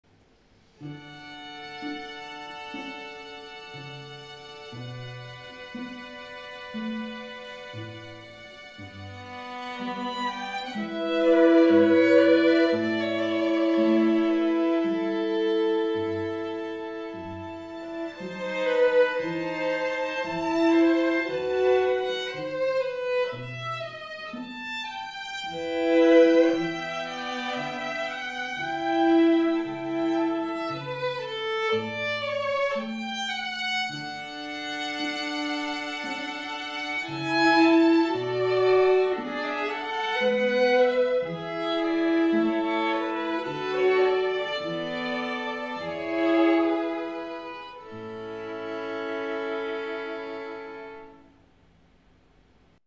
L’Inviti String Quartet
3. Quartet comprising two violins, viola and ‘cello, but…
37. classical